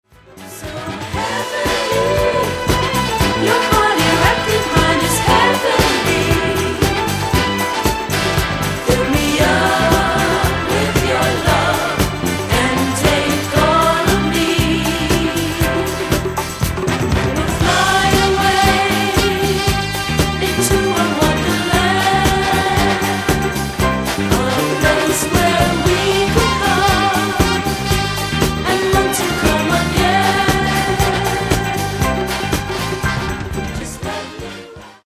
Genere:   Disco | Funky
[12''Mix Extended]